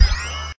CBHQ_CFO_magnet_on.ogg